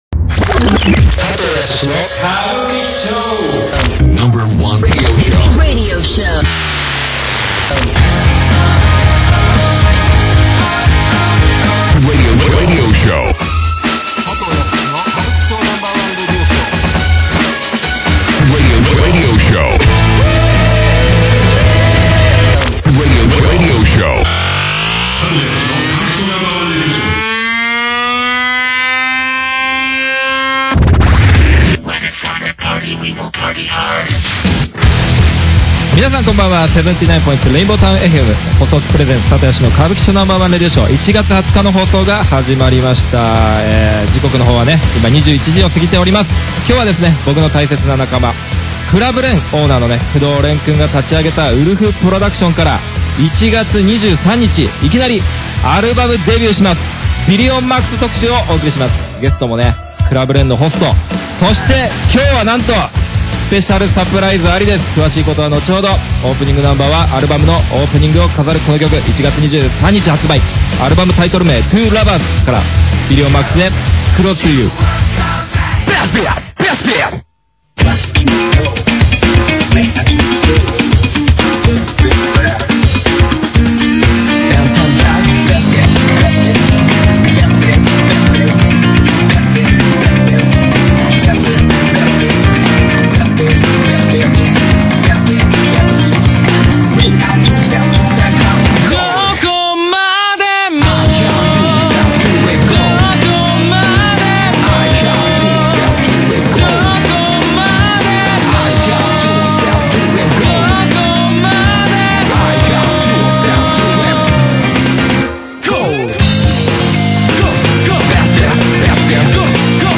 79．2 Rainbowtown FM にて毎週日曜21時から生放送中…!! ※尚、下記放送音源の曲は著作権の都合で割愛させただいておりますのでご了承下さい。